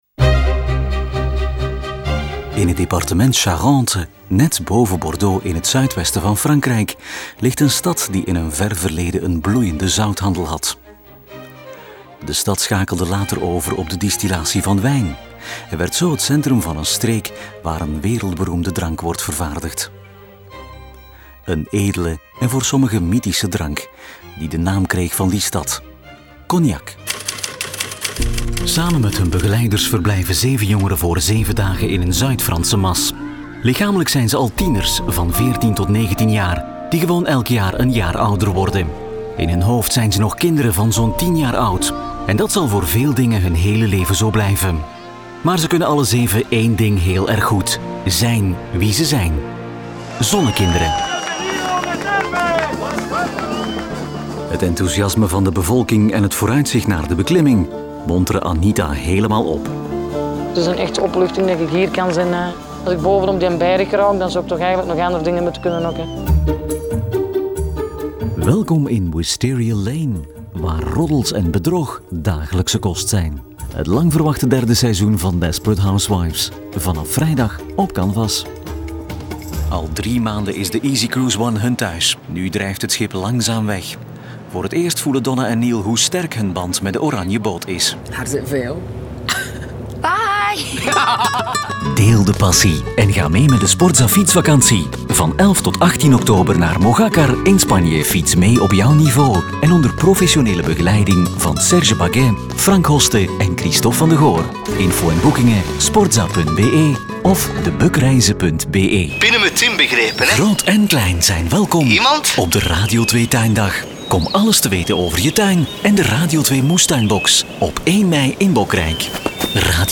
Sprecher
Kommerziell, Tief, Zuverlässig, Warm, Corporate
Audioguide